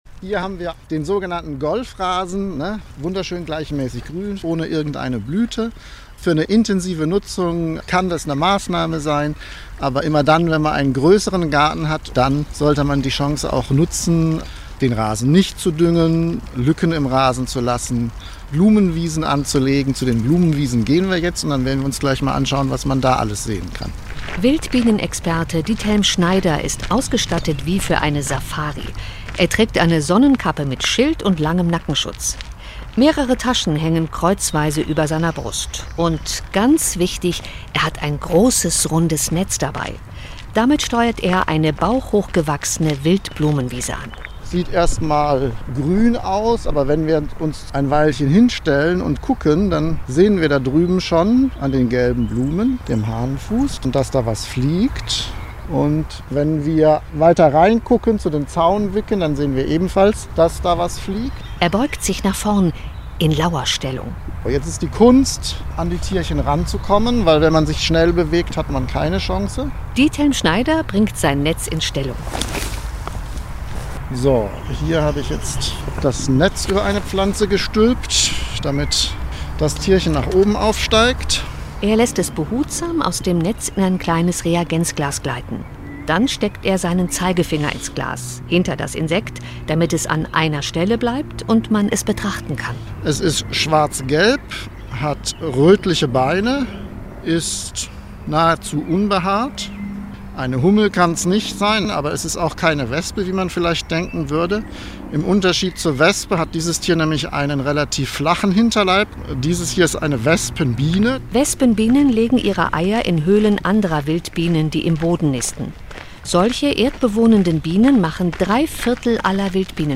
Hier die fertige (auf Sendgungsformat zusammengeschnittene) Sendung.